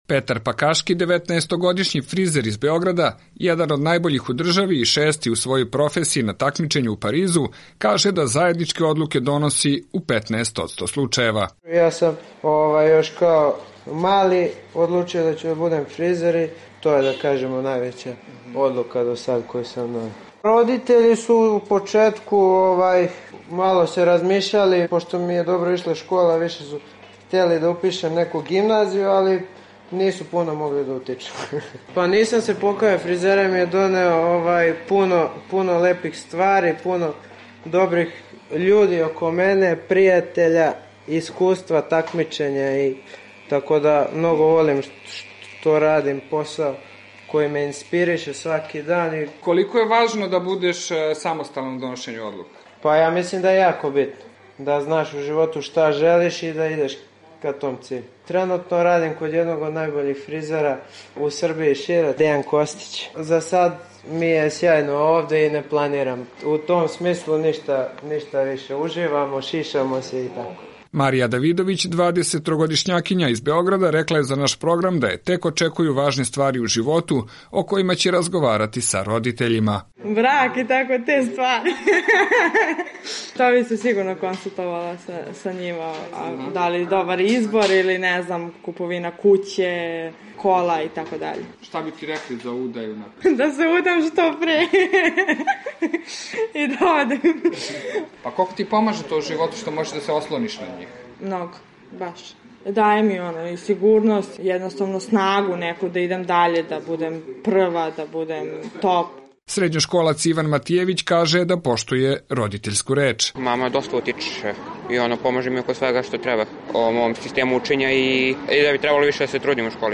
Mladi Beograđani o donošenju odluka